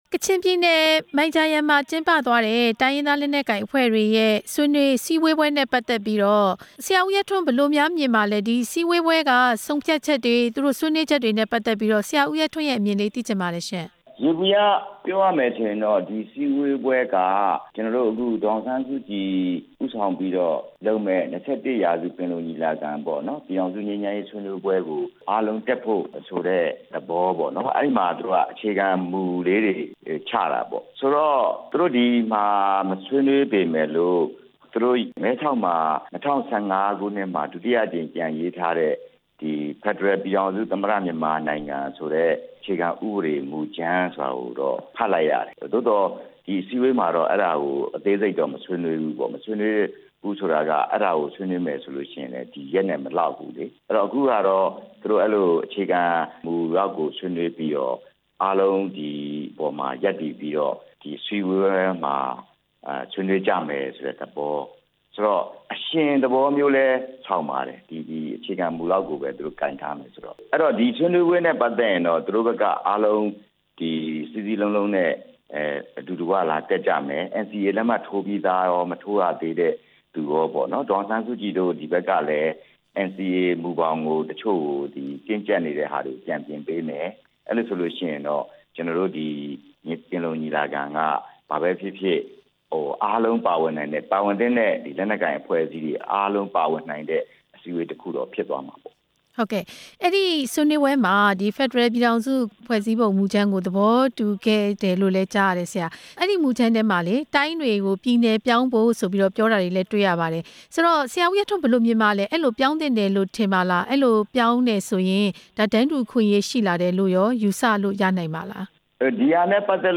SNDP လွှတ်တော်ကိုယ်စားလှယ်ဟောင်း ဦးရဲထွန်းနဲ့ မေးမြန်းချက်